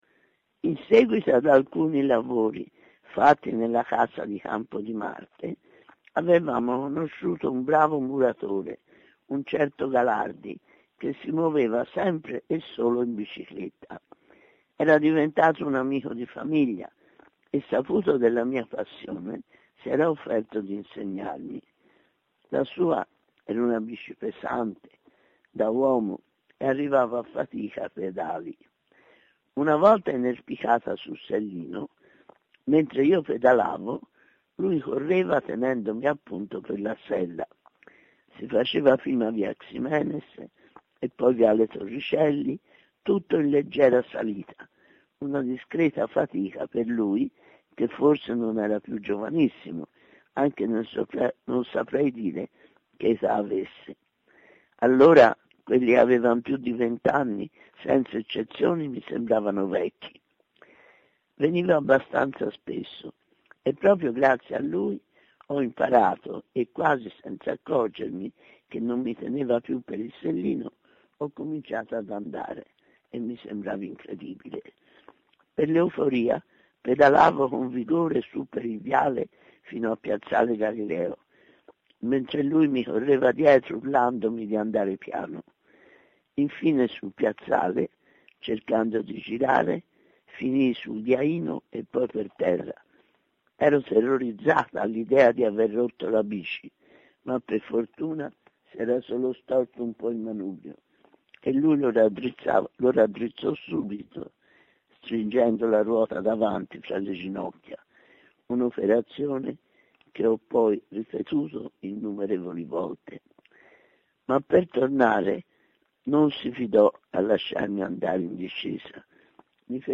Audio Book Trailer · in evidenza · Mountain Book · Narrativa · Trento Film Festival 60 · audio book trailer · audio lettura · Ediciclo · Ediciclo Editore · experience · La mia vita in bicicletta · letture · Margherita Hack · Mountain Book · Ediciclo · Audio Trailer · Narrativa · Festival ed Eventi Cinematografici
Per Mountainblog, Margherita Hack ha letto alcune pagine del suo libro “La mia vita in bicicletta”: